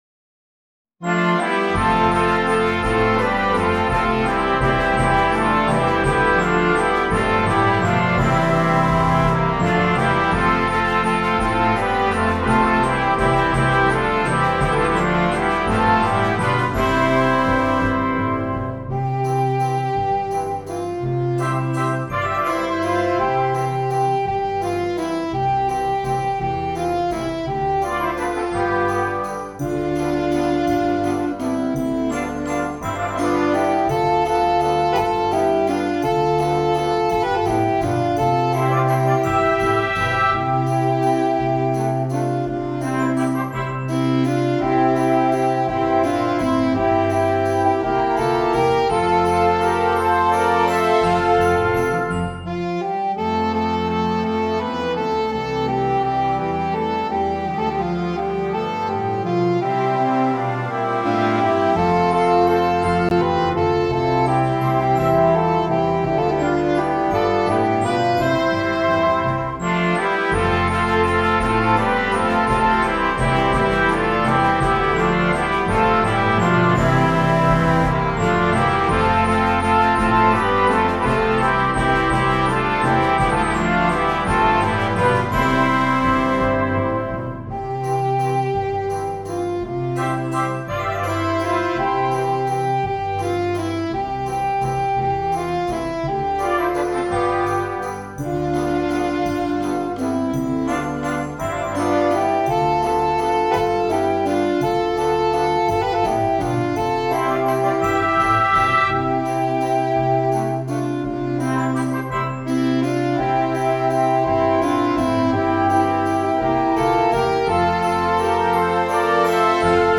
Concert Band
Traditional Mexican Folk Song